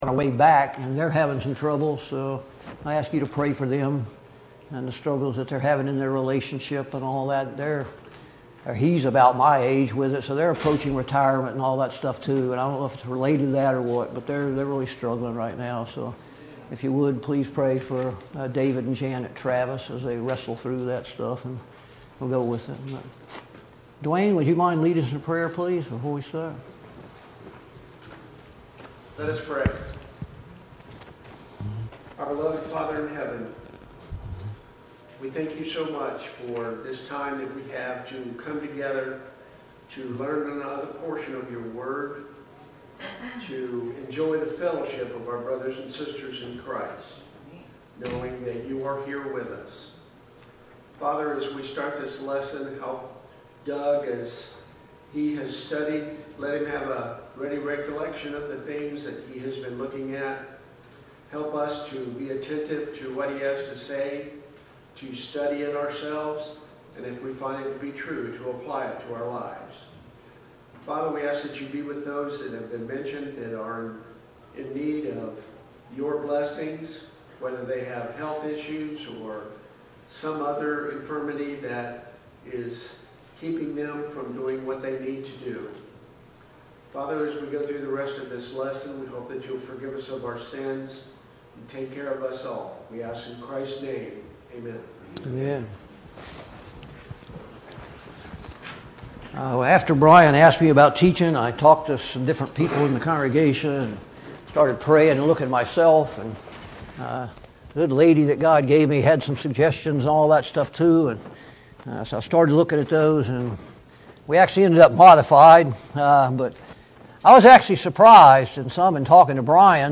Sunday Morning Bible Class